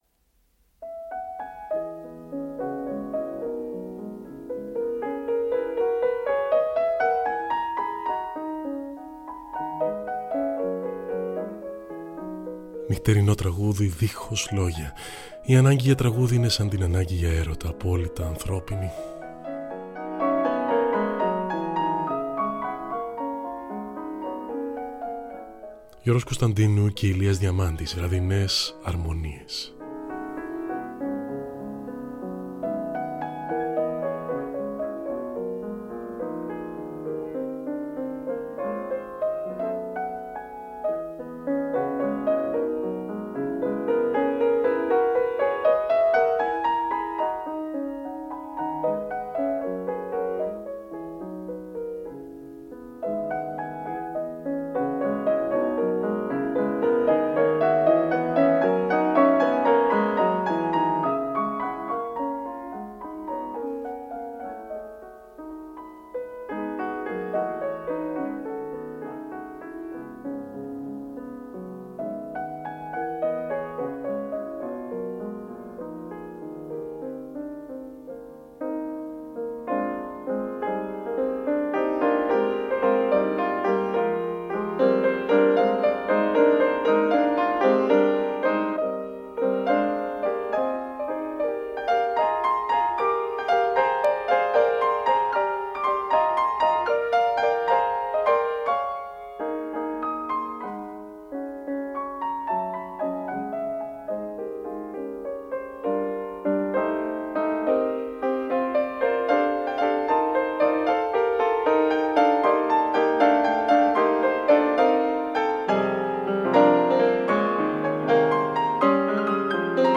Στις Βραδινές Αρμονίες της Τετάρτης: Rorem , Vasks , Tchaikovsky
Φθόγγοι της νύχτας σαν αστέρια που λάμπουν στο πλέγμα του χρόνου… μουσική του τότε, του τώρα… αιώνια μουσική.